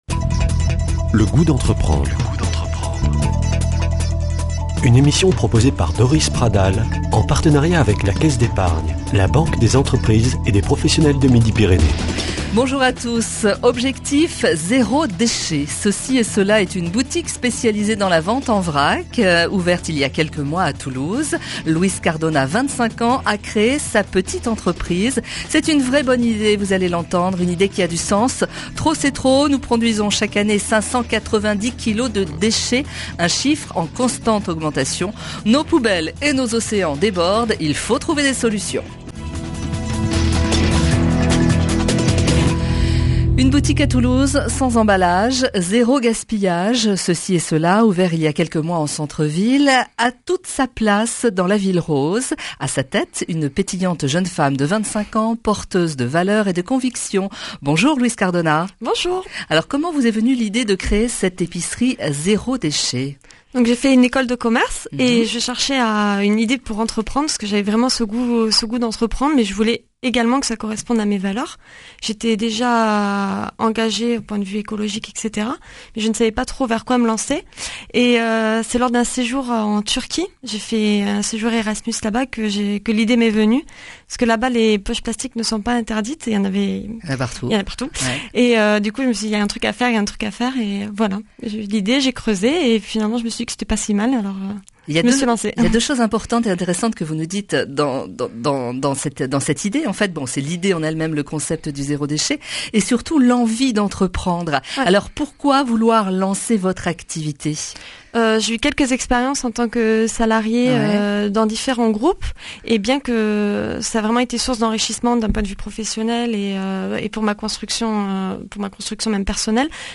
Speech